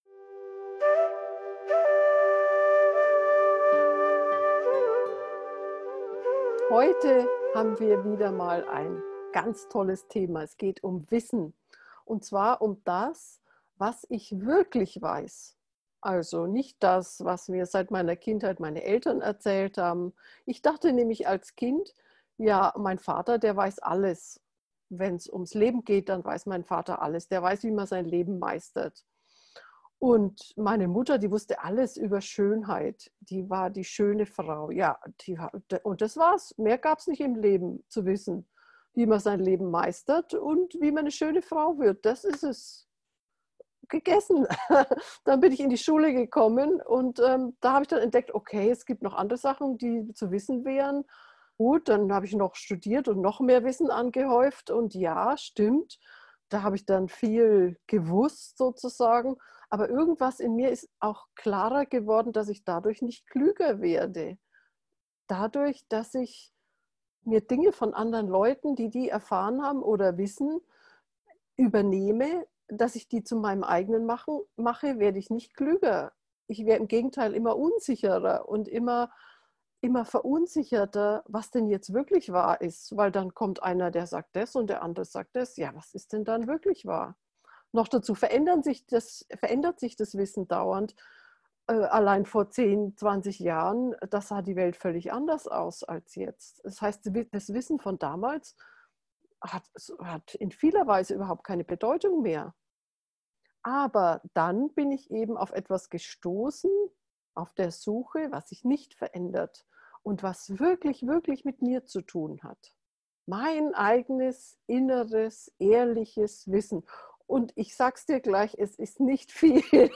Folge 34: Was ich wirklich weiß bemuttern – geführte Meditation